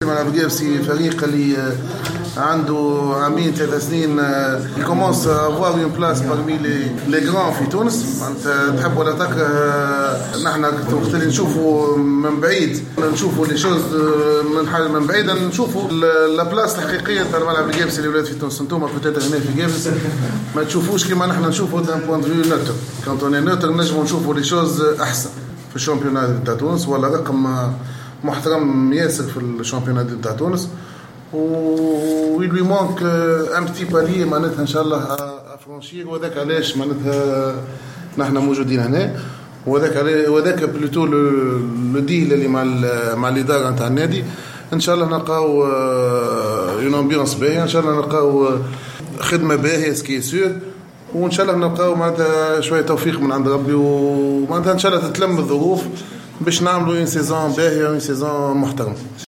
عقد الملعب القابسي اليوم ندوة صحفية بمقر الفريق لتقديم مدربه الجديد ماهر الكنزاري الذي سيخلف الفرنسي جيرار بوشار .